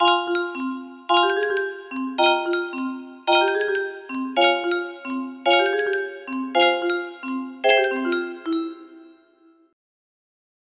Moon - X1 music box melody